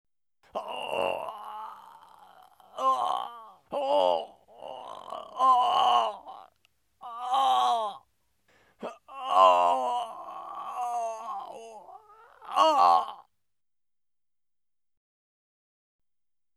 Scary Sounds - 31 - Groaning Man.mp3